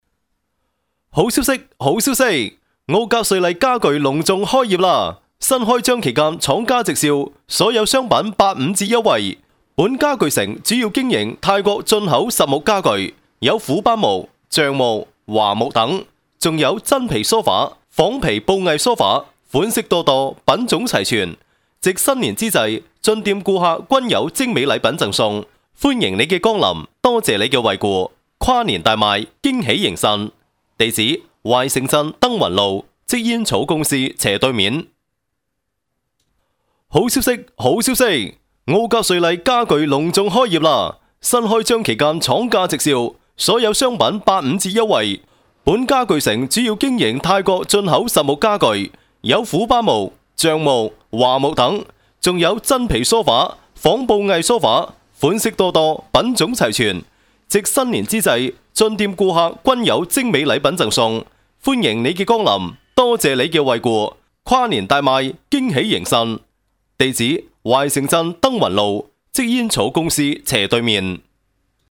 Kantonca (Honkong) Seslendirme
Erkek Ses